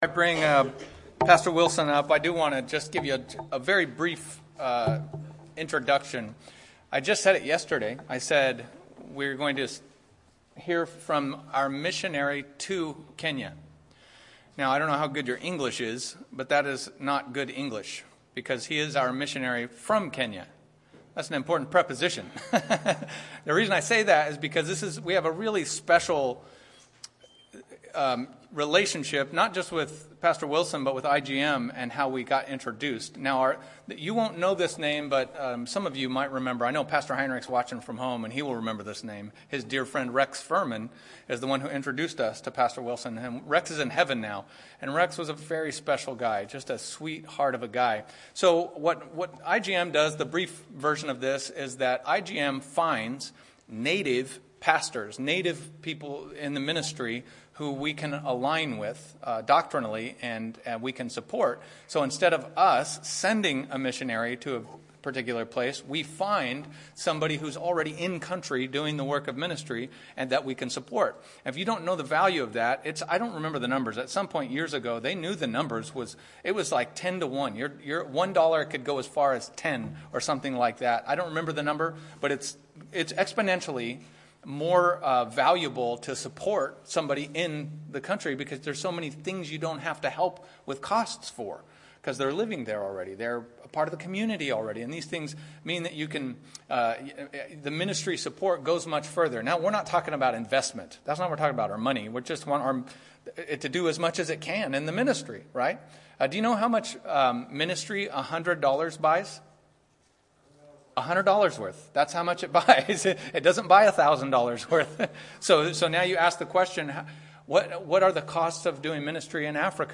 Sermon and Report from Kenya